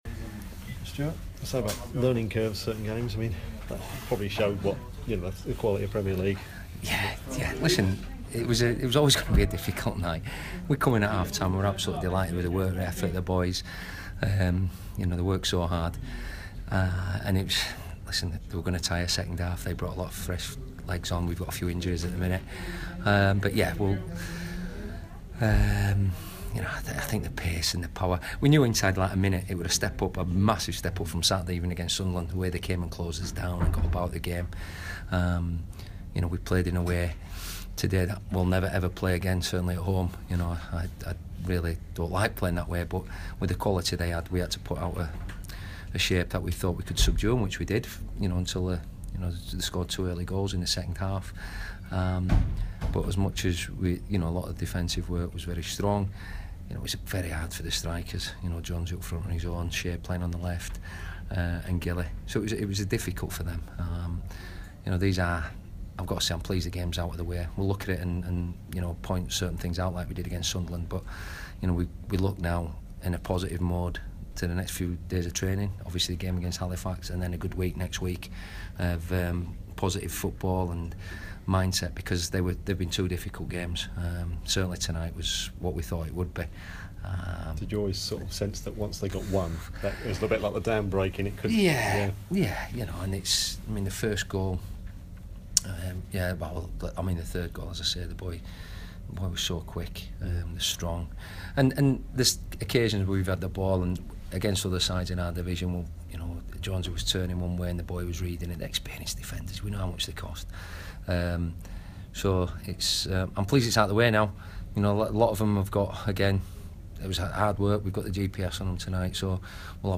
Bradford City boss Stuart McCall speaks to the media after his side's 4-0 defeat to Newcastle United.